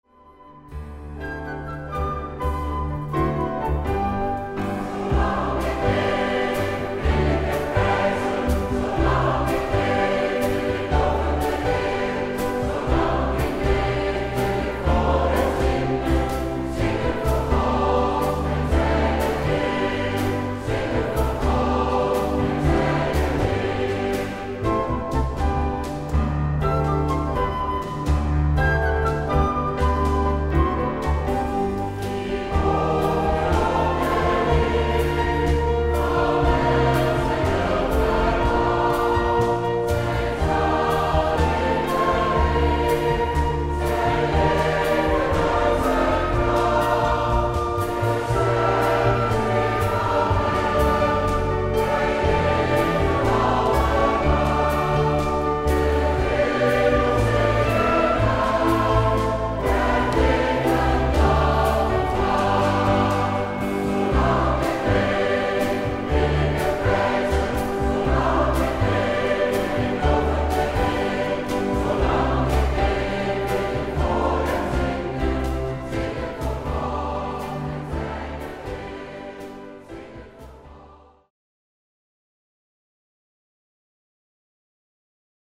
trompet
bugel
hobo
dwarsfluit
contrabas
slagwerk
vleugel
orgel
SATB